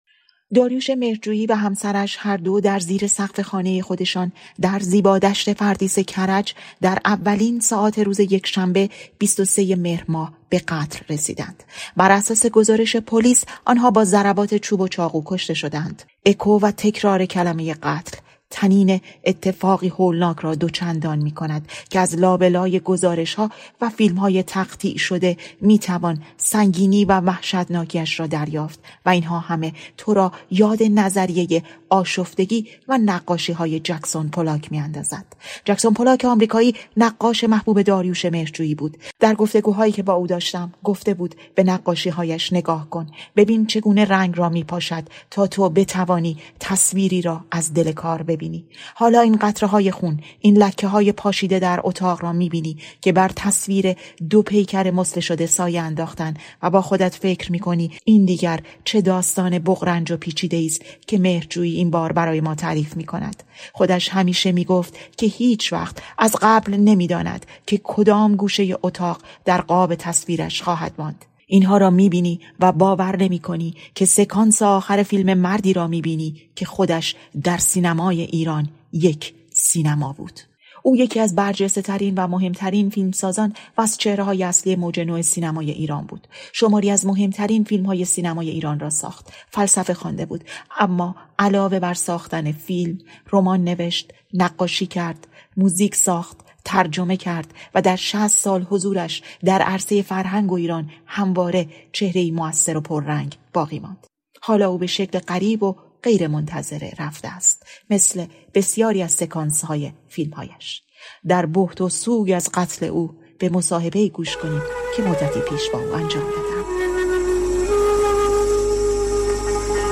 گفت‌وگو با مهرجویی که بزرگ‌ترین مشکل همیشگی‌اش سانسور بود
داریوش مهرجویی به‌شکلی غریب و غیرمنتظره رفته است، مثل بسیاری از سکانس‌های فیلم‌هایش. در بهت و سوگ قتل او، به صدای او گوش کنیم در آخرین گفت‌وگویی که با رادیوفردا انجام داد.